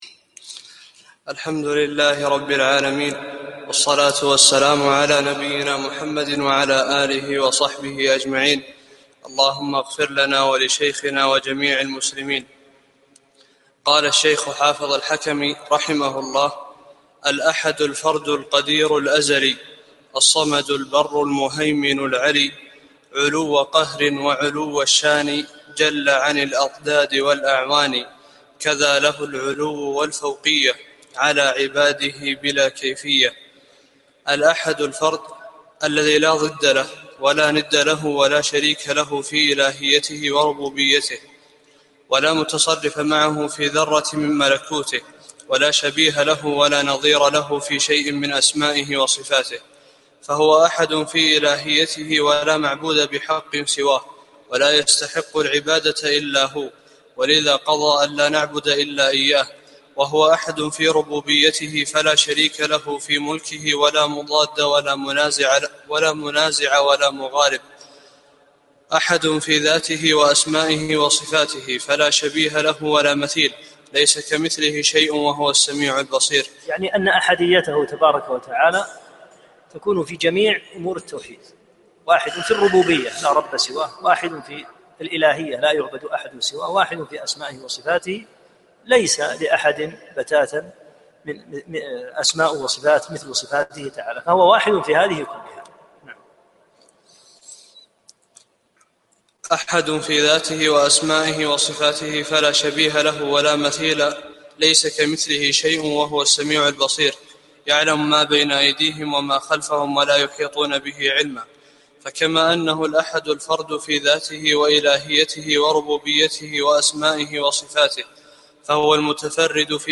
13- الدرس الثالث عشر